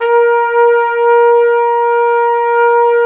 Index of /90_sSampleCDs/Roland L-CD702/VOL-2/BRS_Flugel Sect/BRS_Flugel Sect